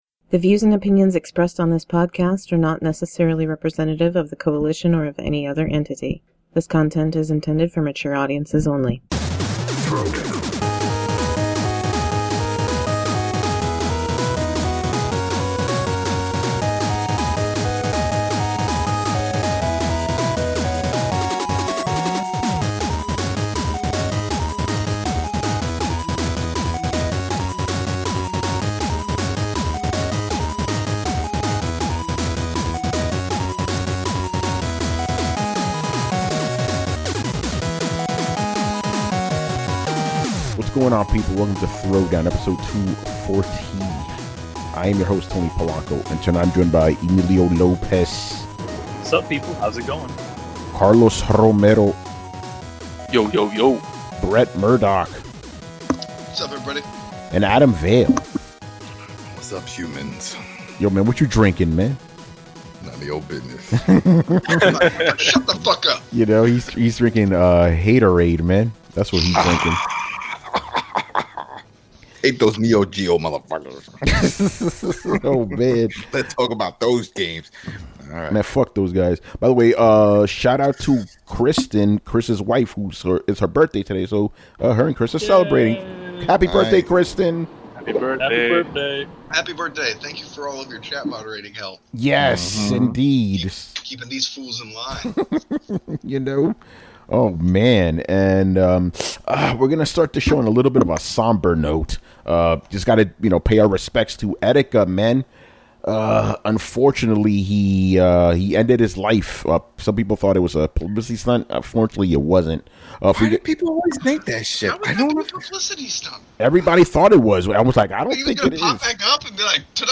On this podcast/vidcast we discuss the latest video game news and topics in an uncompromising and honest manner.